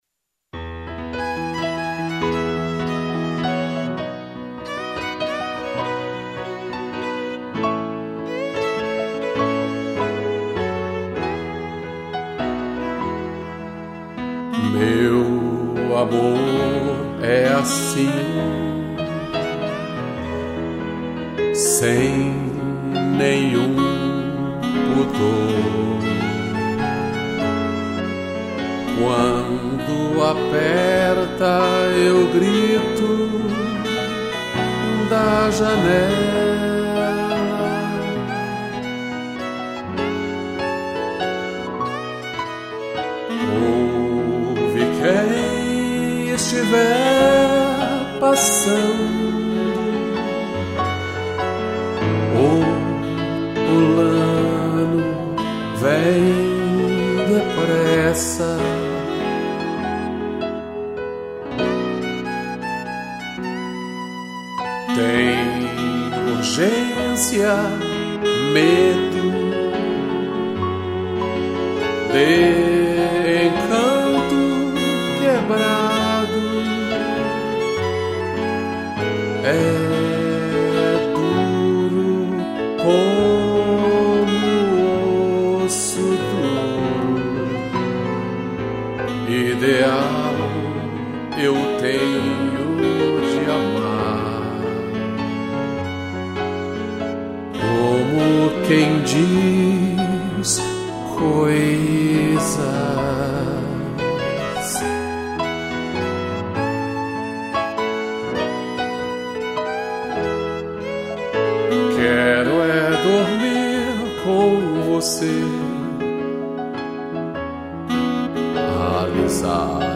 voz
2 pianos e violino